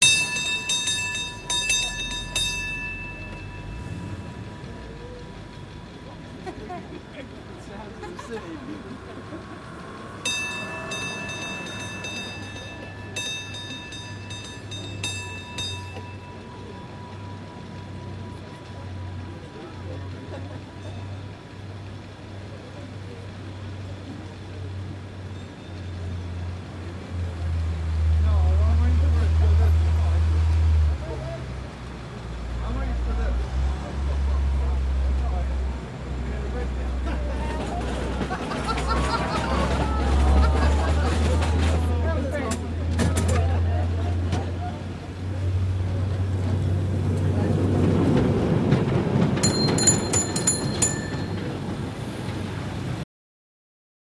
Cable Car, San Francisco, Idle and Away, Close With Walla and Bells